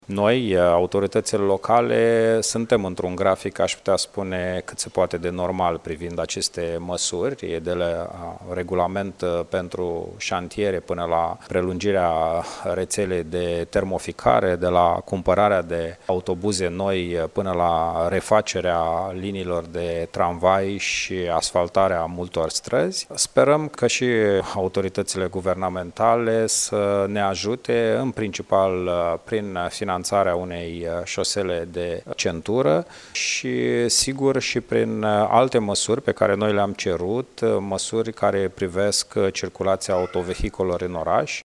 Viceprimarul de Iaşi, Radu Botez: